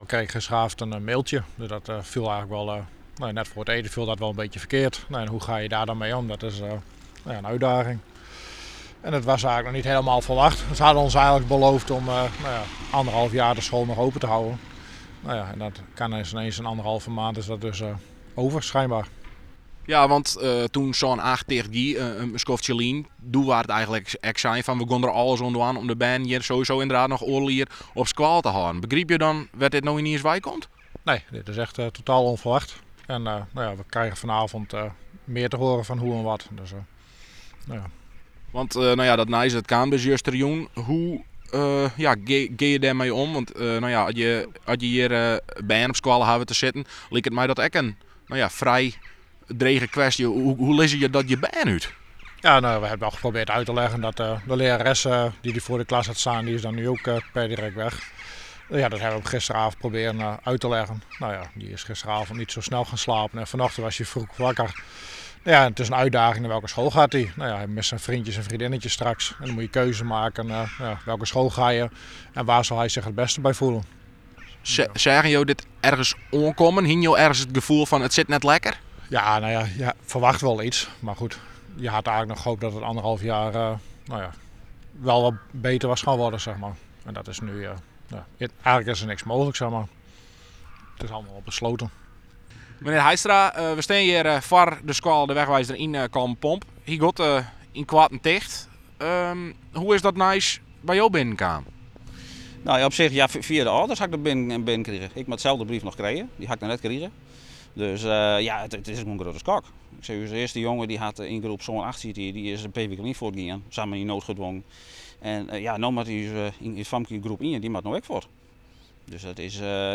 bij CBS De Wegwijzer